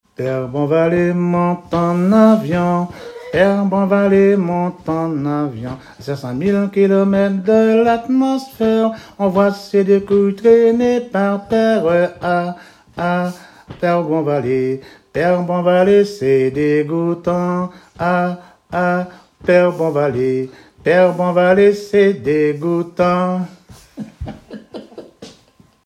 Enfantines - rondes et jeux
chansons de traditions orales
Pièce musicale inédite